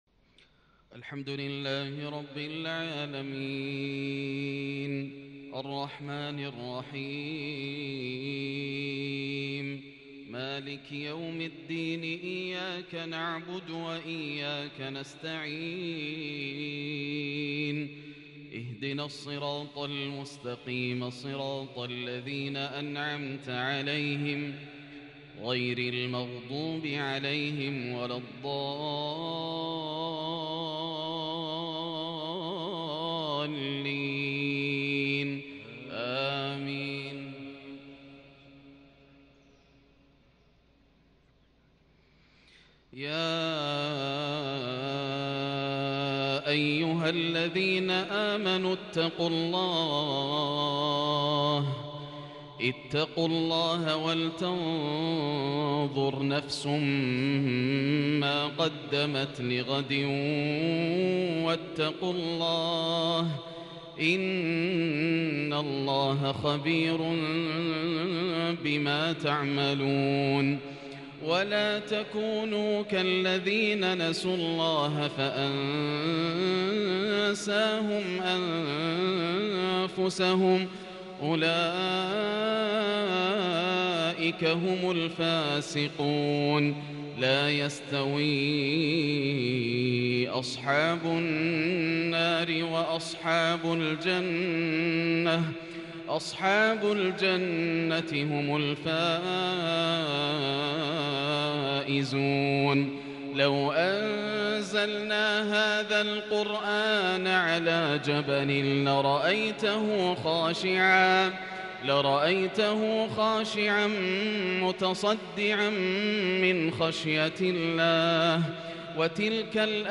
صلاة العشاء الخميس 7-2-1442هـ | من سورة الحشر و الإنفطار | | Isha Prayar from Surah AlHashr and Surah AlInfitar /24/9/2020 > 1442 🕋 > الفروض - تلاوات الحرمين